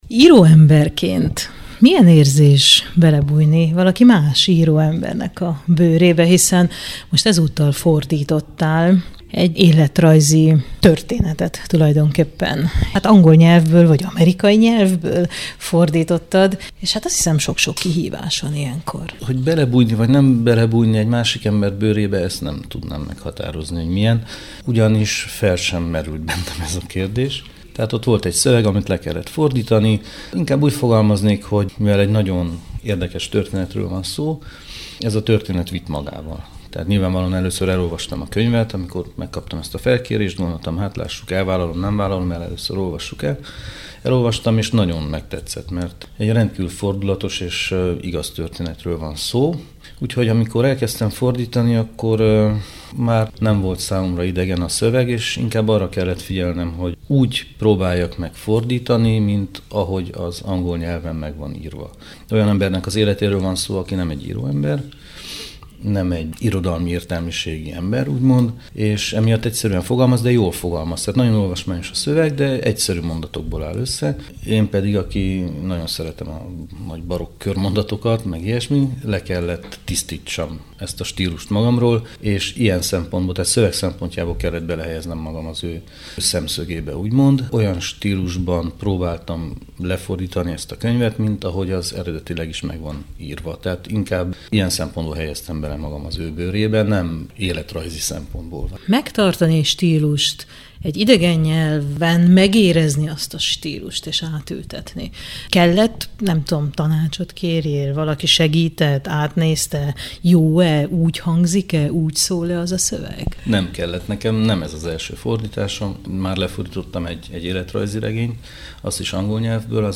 beszélgetünk a Szabadság éjféli vonata című kisregény fordításáról